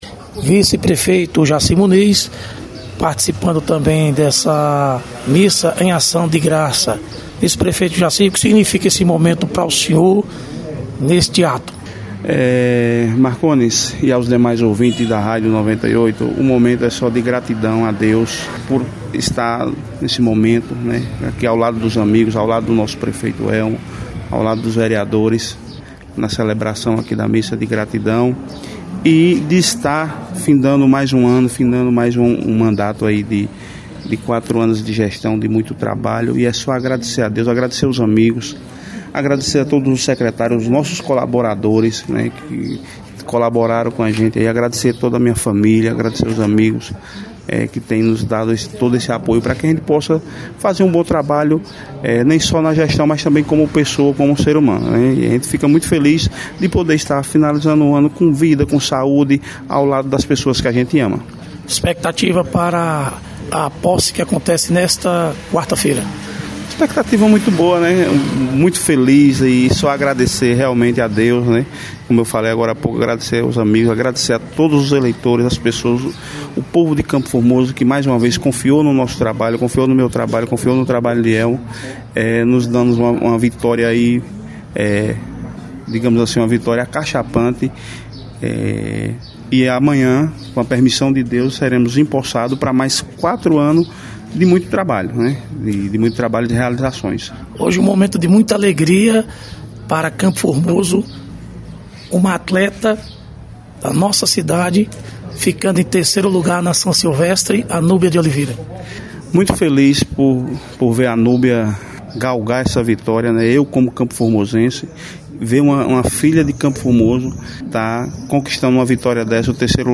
Vice-prefeito, Jaci Muniz – participação da missa em ação de graça aos eleitos de Campo Formoso